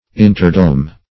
Search Result for " interdome" : The Collaborative International Dictionary of English v.0.48: Interdome \In"ter*dome`\, n. (Arch.) The open space between the inner and outer shells of a dome or cupola of masonry.